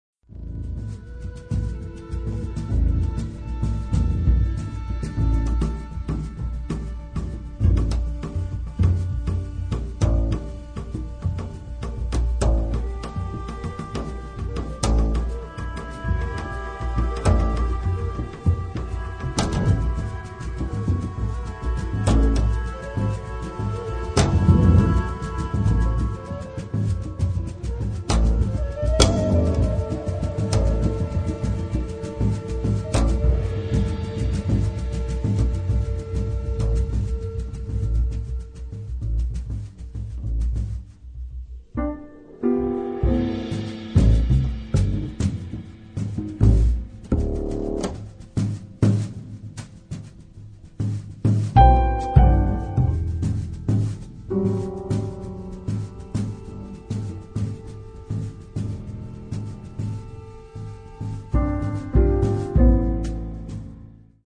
Contrabbasso
batteria
piano, Fender Rhodes, xenophone
kaval (flauto balcanico “Chaval”)